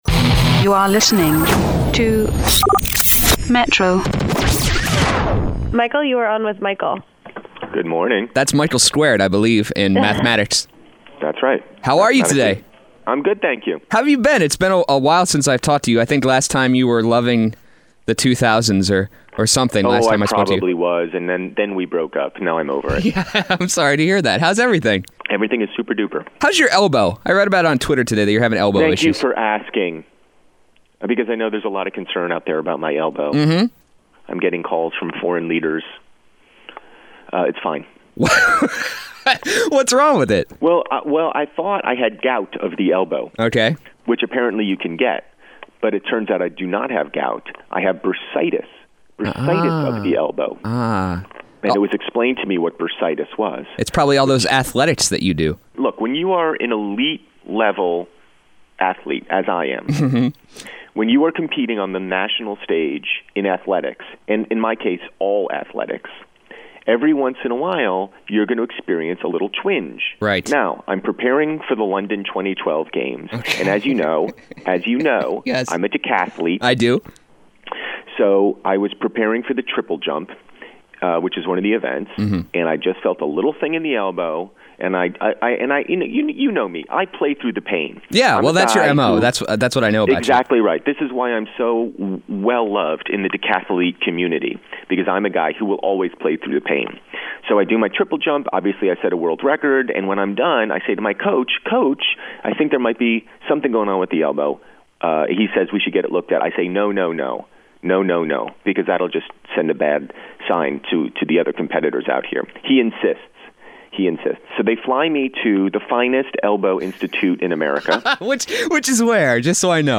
But first an intimate chat with one of the funniest people I know via telephone.